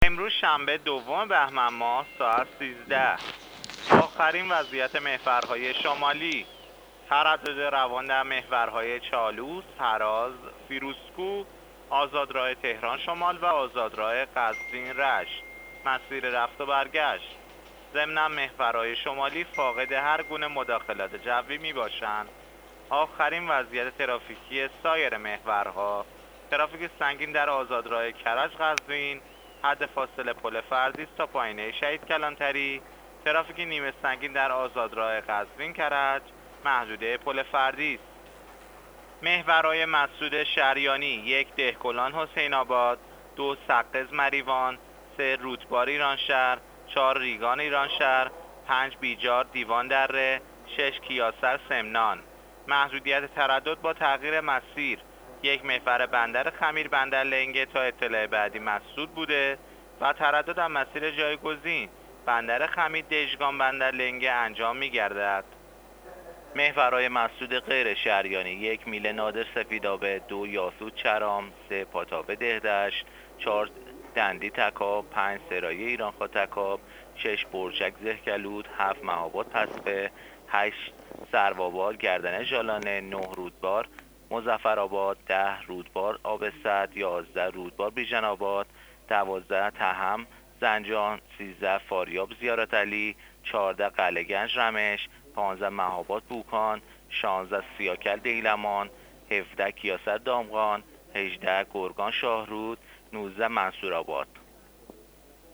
گزارش رادیو اینترنتی از آخرین وضعیت ترافیکی جاده‌ها تا ساعت ۱۳ دوم بهمن؛